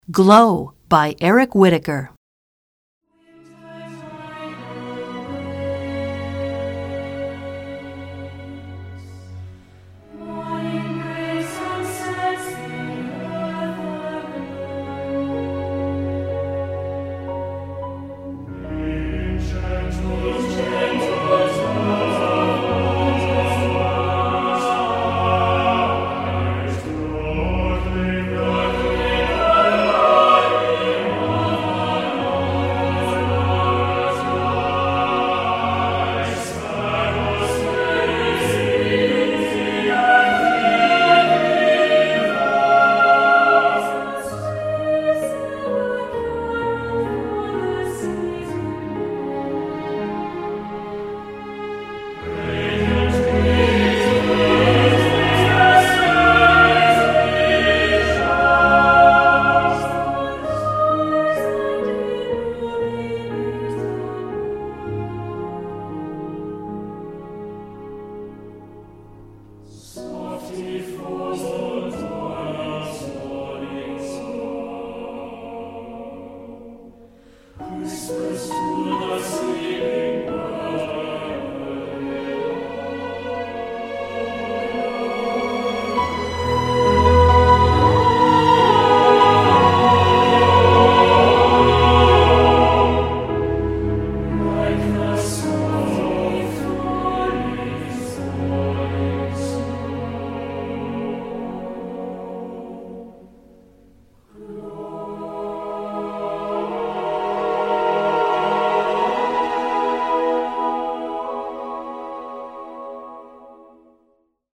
Choral Christmas/Hanukkah Concert/General
Voicing
SAB